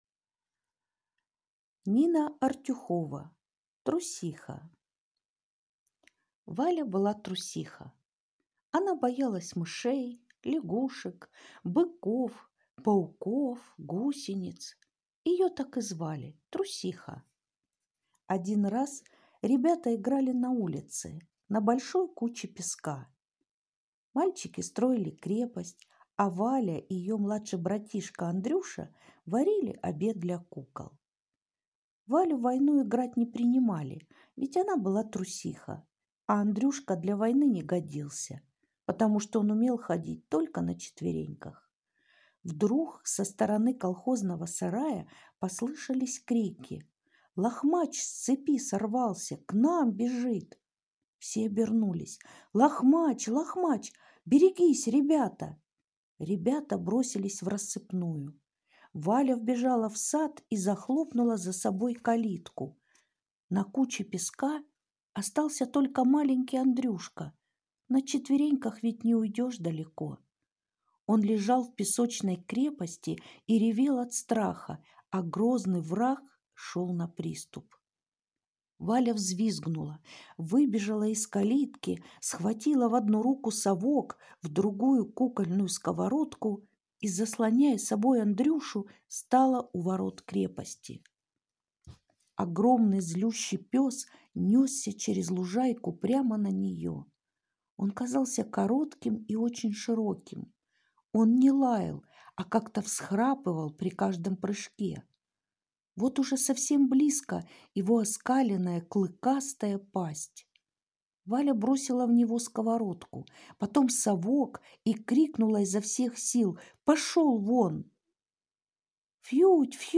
Аудиорассказ «Трусиха Артюхова Н.М.»